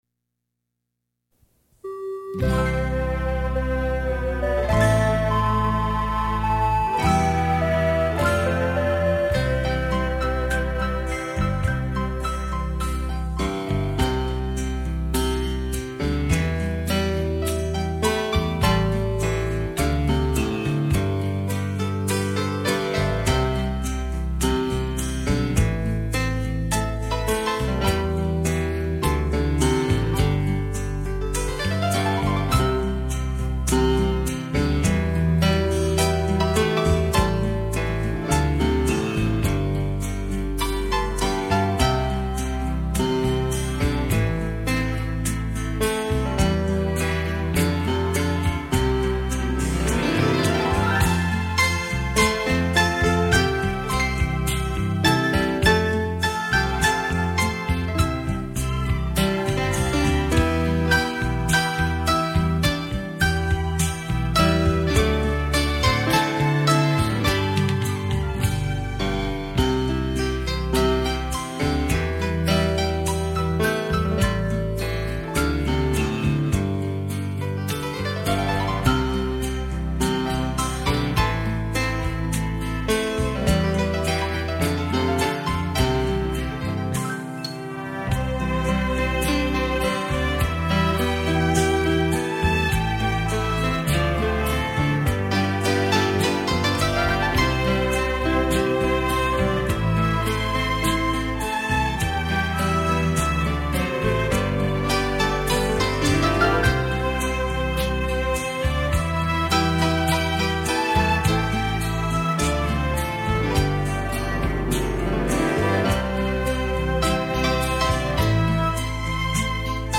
浪漫钢琴&浪漫小提琴
钢琴的琴键与小提琴的琴弦 交织出浪漫美丽的生活情趣